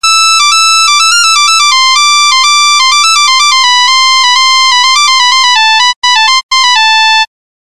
NokiaSamsung рингтоны. Арабские
(народная)